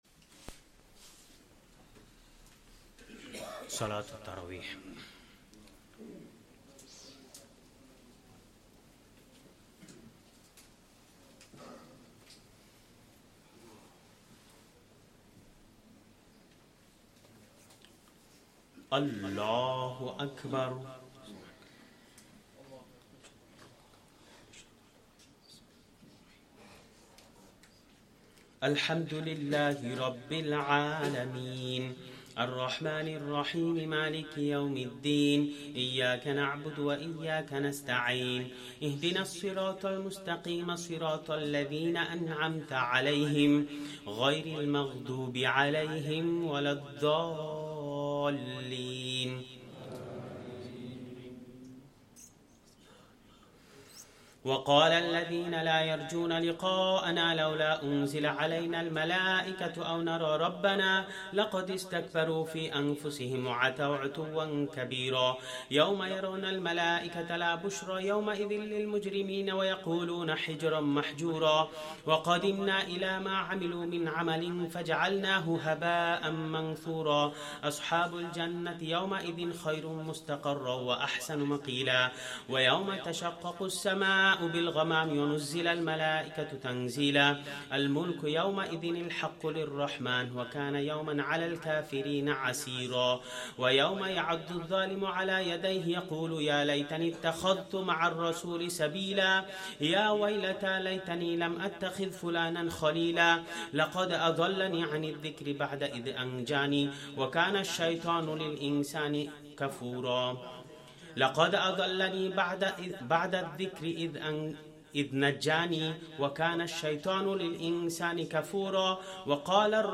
2nd Tarawih prayer - 17th Ramadan 2024